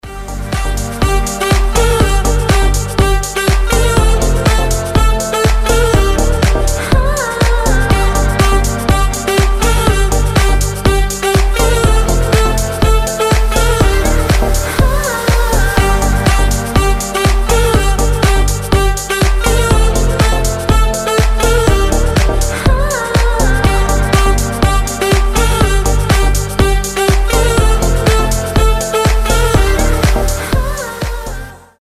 • Качество: 320, Stereo
громкие
восточные мотивы
женский голос
без слов
Саксофон
Зажигательная мелодия